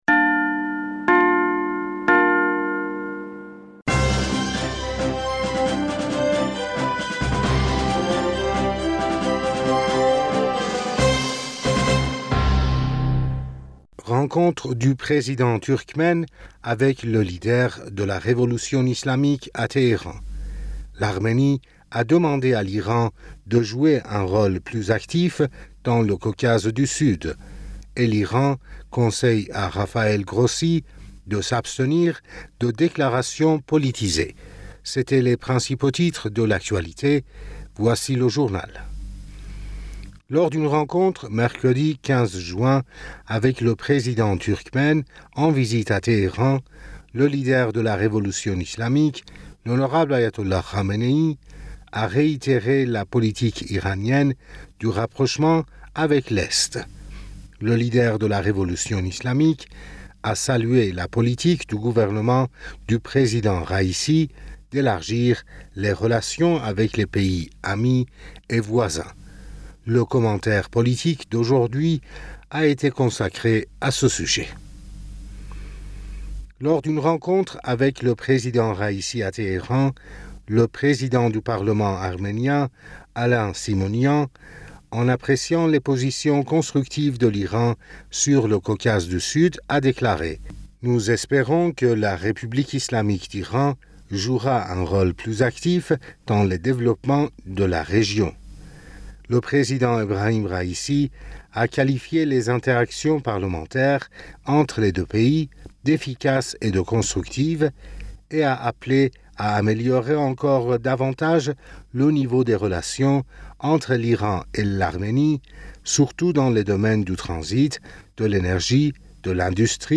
Bulletin d'information Du 16 Juin